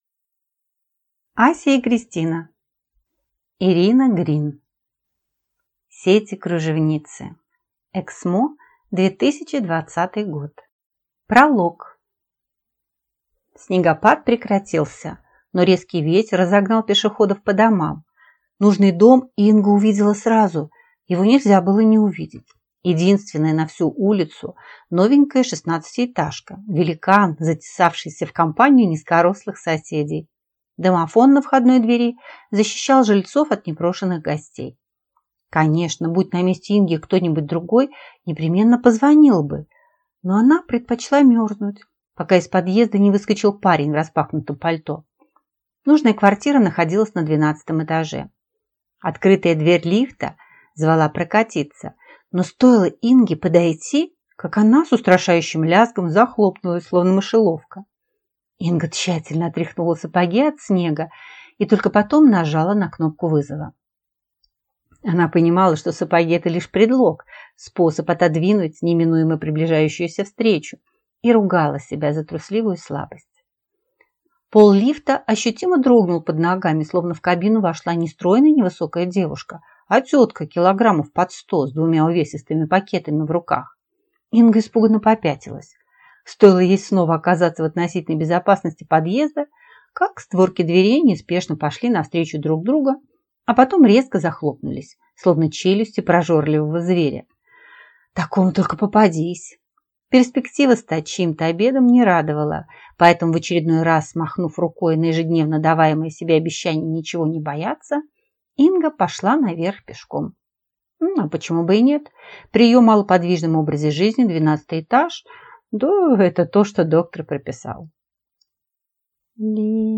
Аудиокнига Сети кружевницы | Библиотека аудиокниг